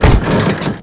compress_truck_2.wav